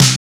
RX SNARE LO.wav